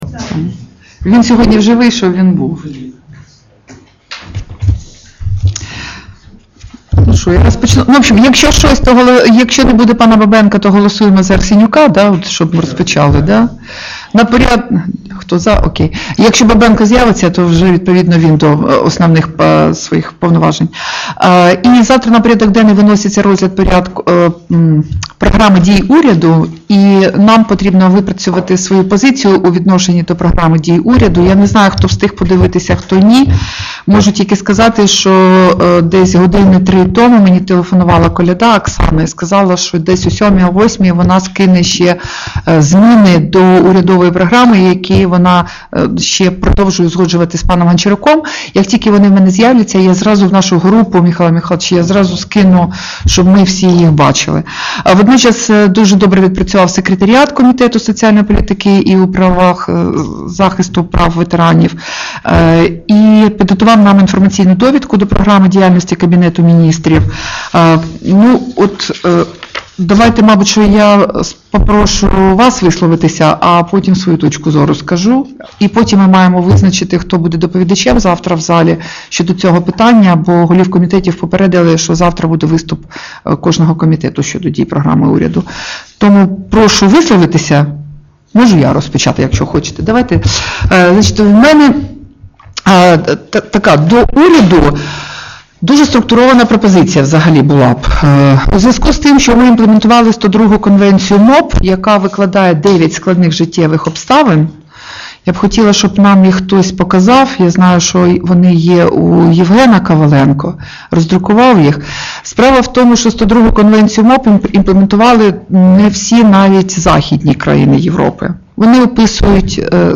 Аудіозаписи засідань Комітету (№1-№3, №6, №8-№10, №12, №17, №18, №20, №23-№28)